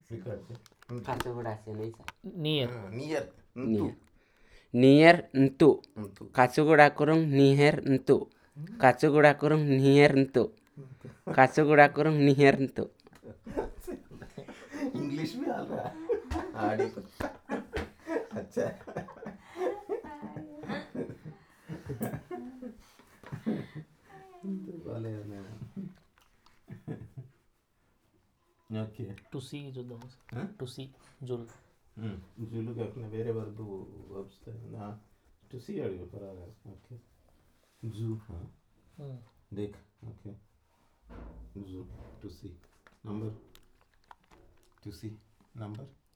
Elicitation of sentences on verb